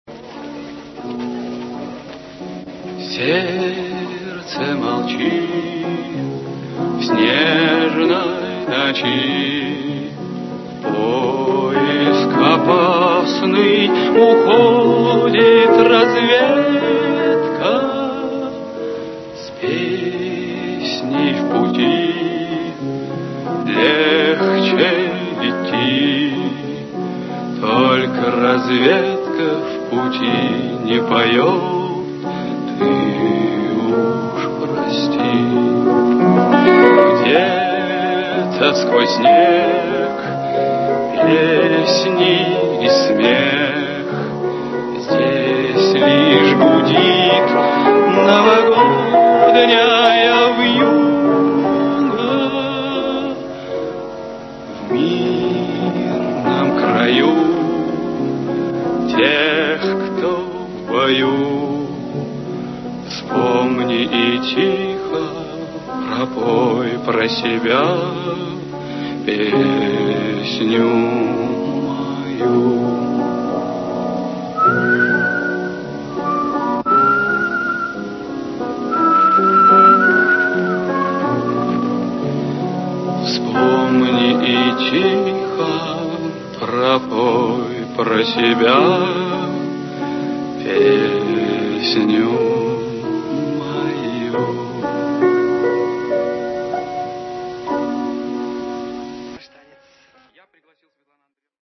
знаменитый вальс